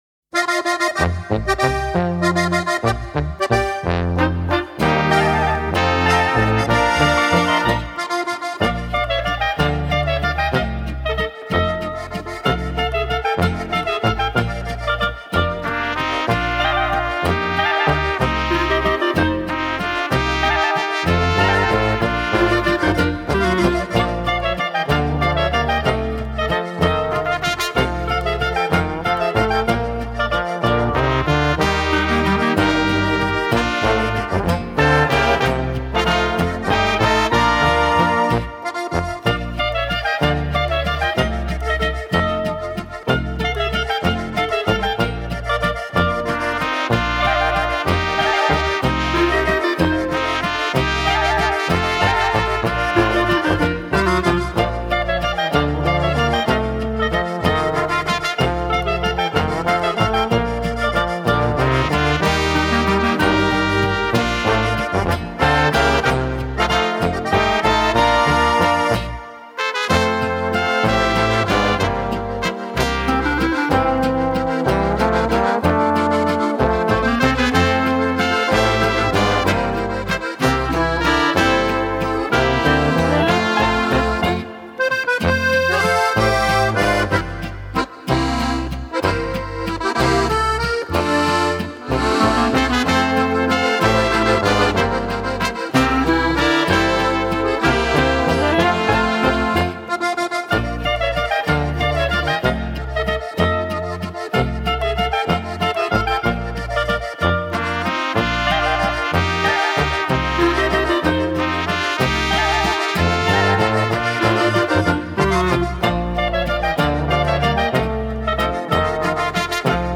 Gattung: für Oberkrainer Besetzung ab 5 bis 9 Musiker
Besetzung: Volksmusik/Volkstümlich Weisenbläser